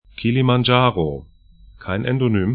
Aussprache
Kilimandscharo Kiliman-'dʒa:ro Kilimanjaro kiliman'dʒa:ro kisu./en Berg / mountain 3°04'S, 37°22'E